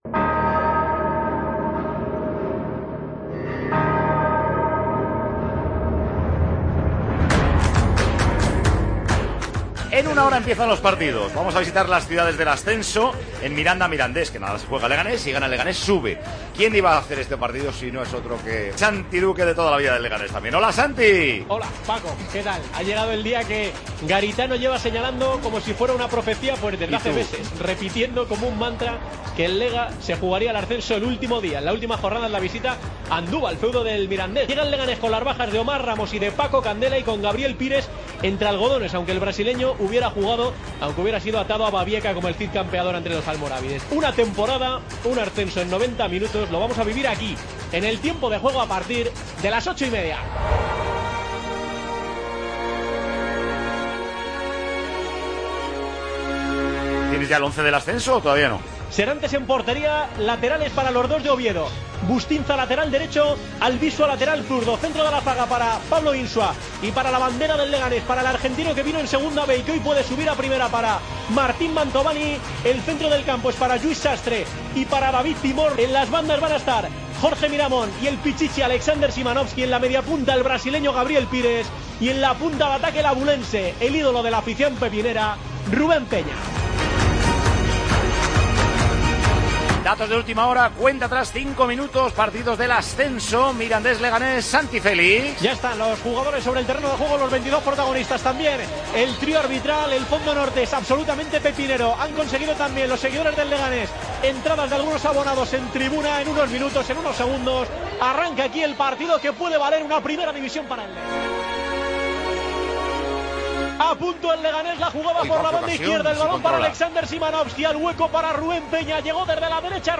AUDIO: Escucha el resumen de cómo se narró en Tiempo de Juego el ascenso del CD Leganés a Primera División.